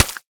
sounds / block / mud / break4.ogg
break4.ogg